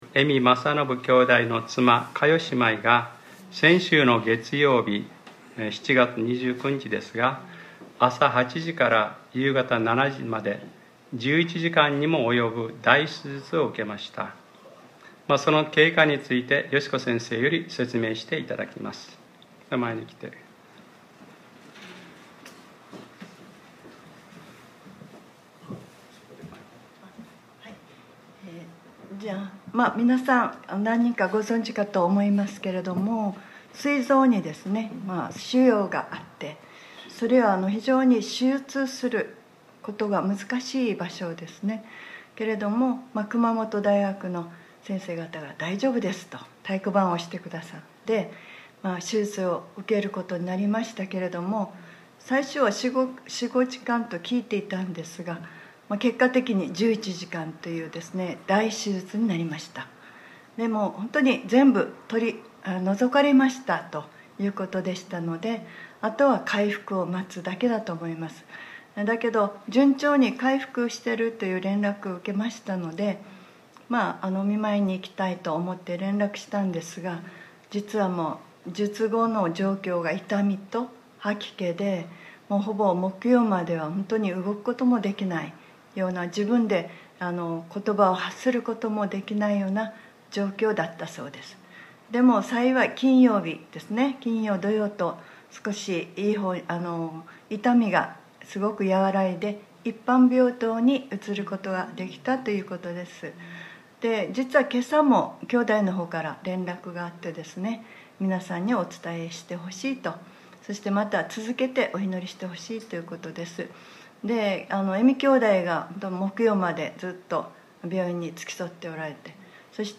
2019年08月04日（日）礼拝説教『神の国は近くなった』